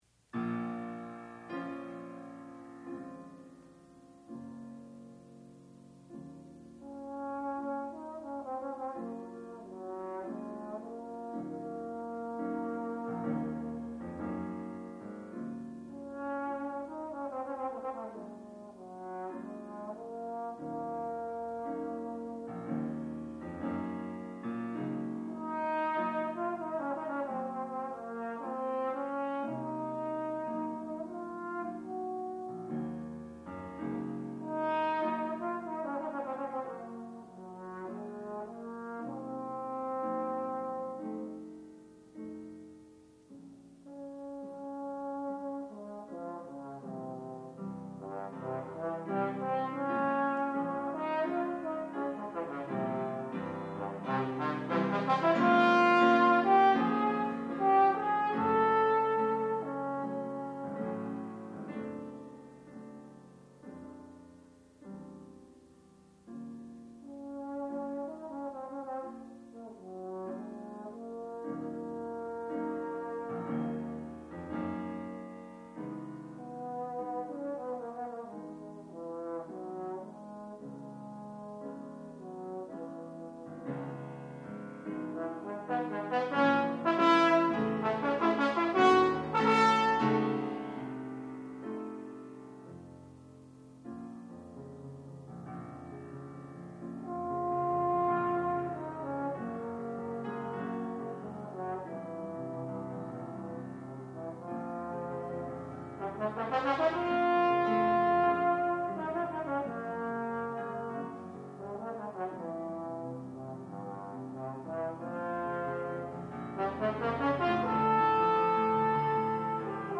sound recording-musical
classical music
Freshman Recital
trombone